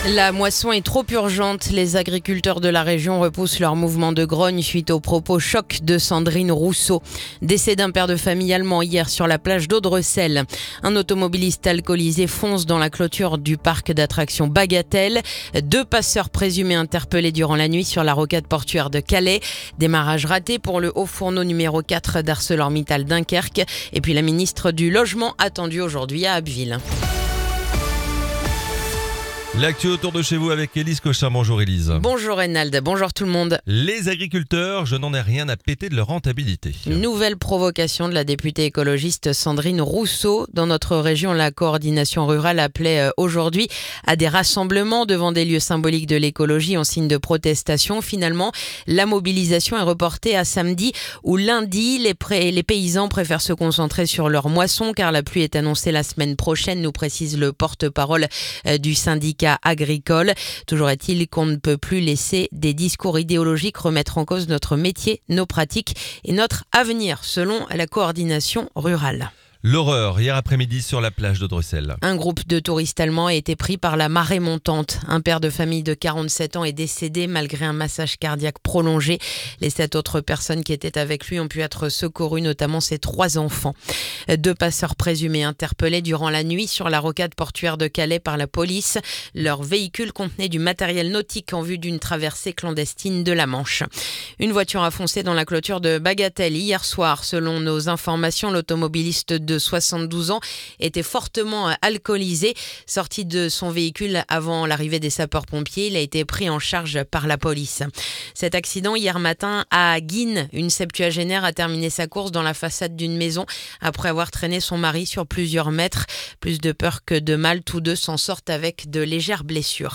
Le journal du jeudi 17 juillet